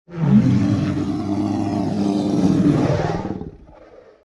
horror
Dinosaur Roaring 3